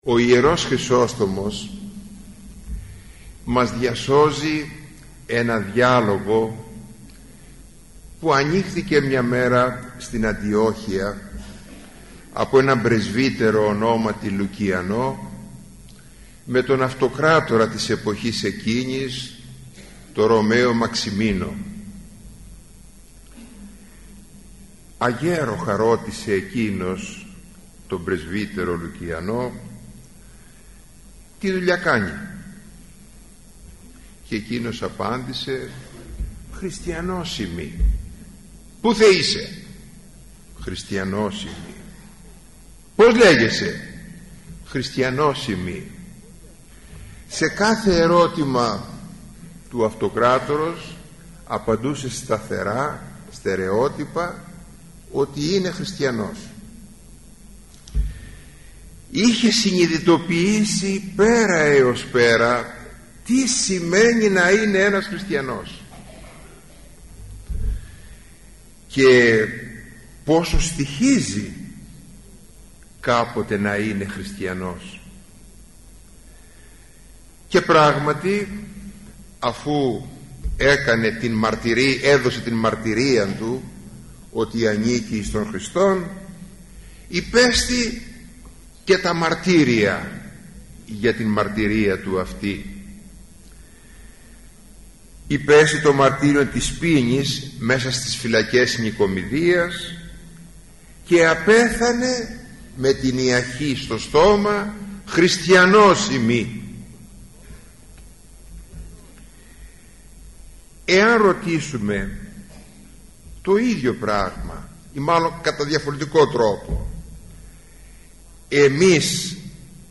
ηχογραφημένη ομιλία
Η ομιλία αυτή “πραγματοποιήθηκε” στην αίθουσα της Χριστιανικής ενώσεως Αγρινίου.